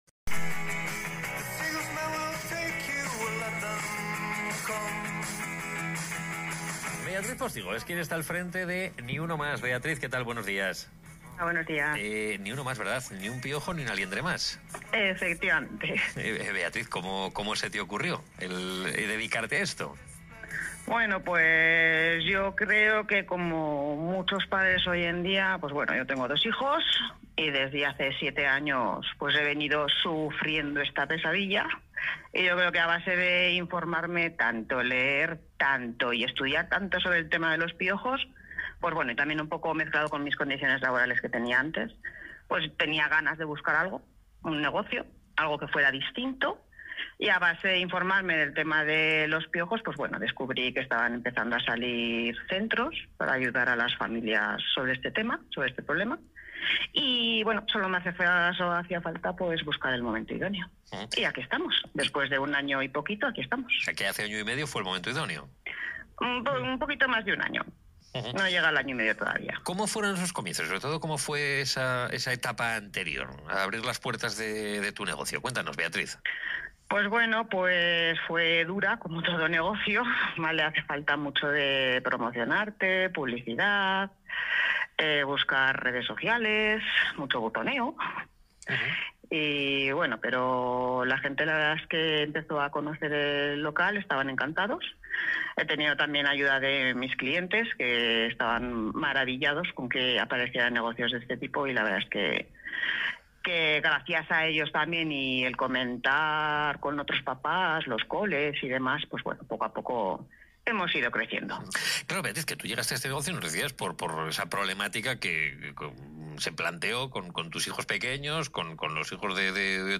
Entrevista Aragón Radio
Entrevista-Aragon-Radio.m4a